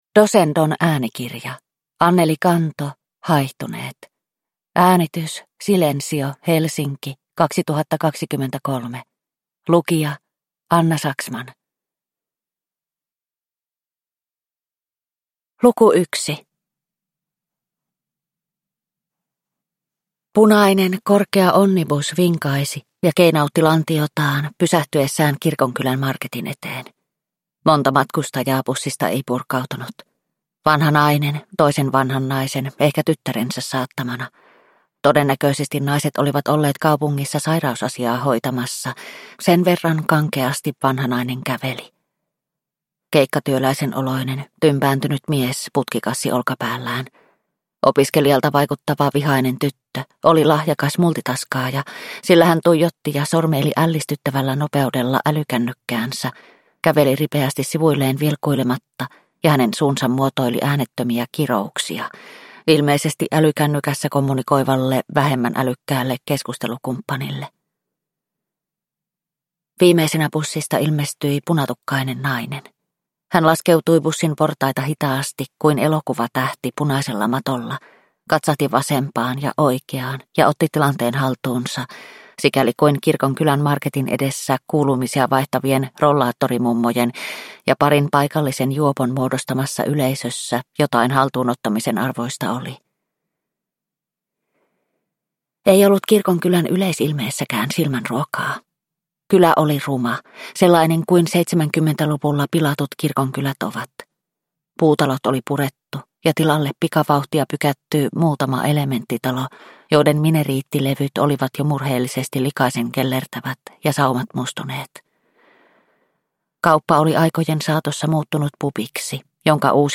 Haihtuneet – Ljudbok – Laddas ner